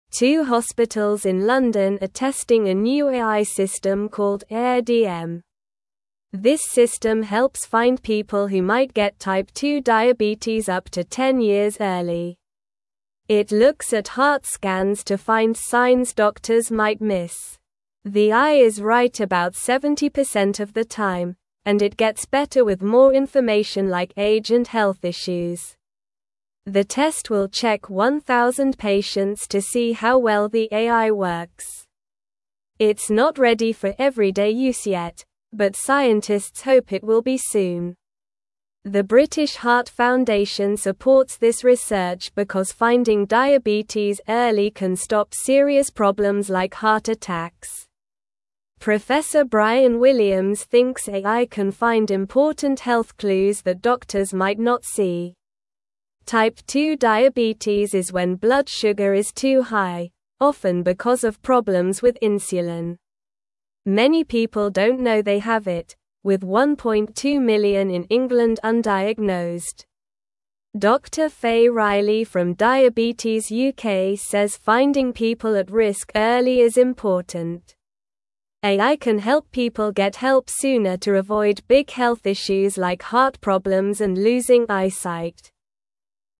Slow
English-Newsroom-Lower-Intermediate-SLOW-Reading-New-Computer-Helps-Find-Diabetes-Early-in-People.mp3